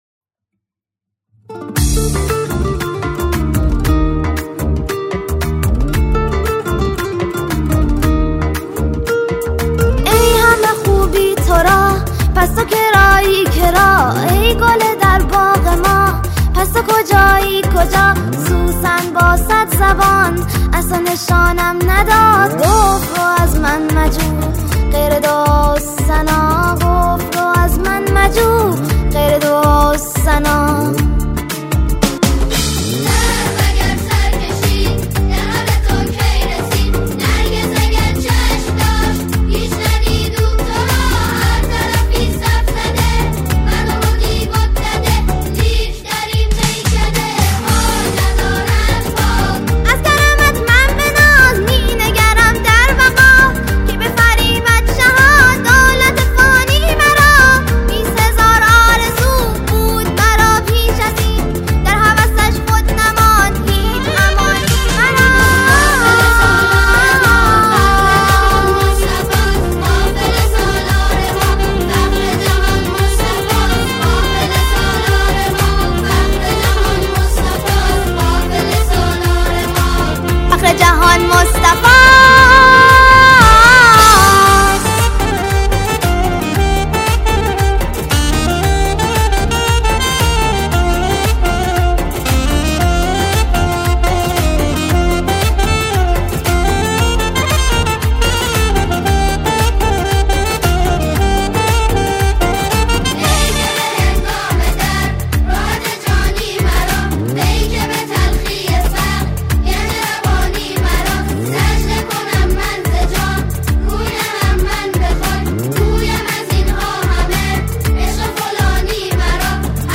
سرودهای ۱۷ ربیع الاول